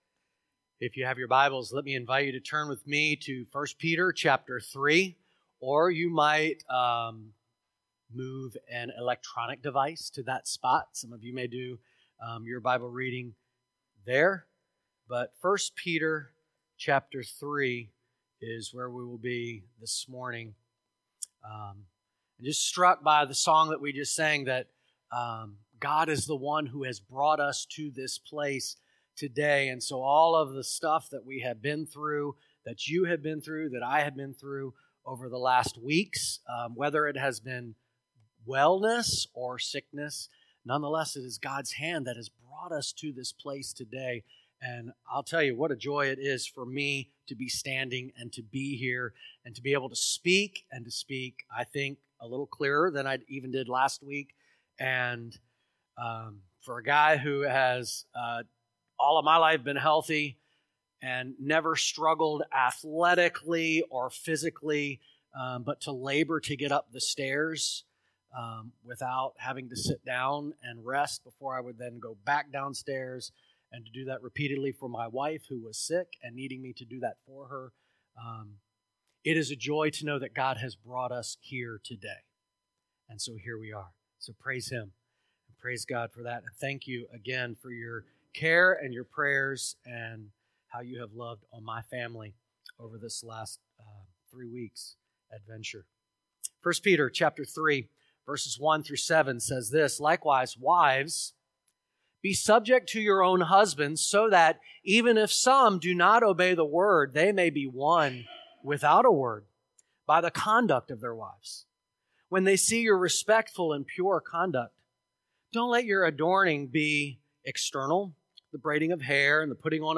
Sermons | Great Commission Baptist Church